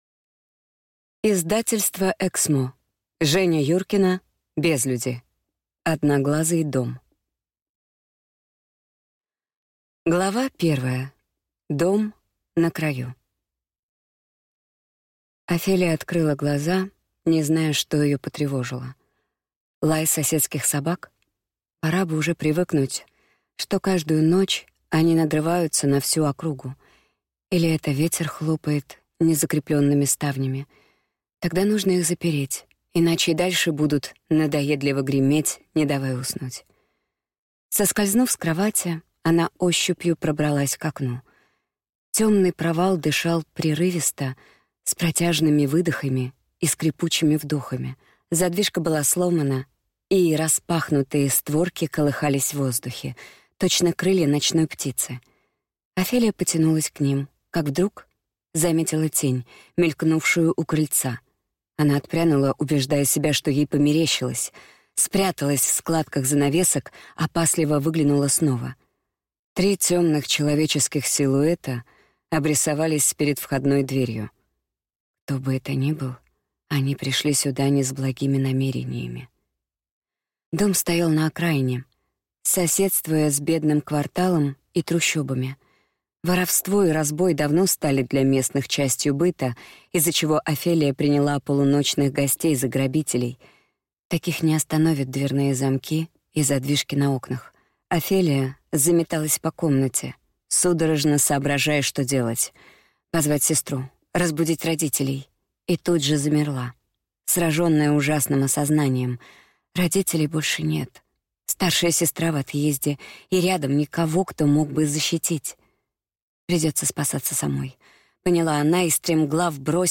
Аудиокнига Безлюди. Одноглазый дом | Библиотека аудиокниг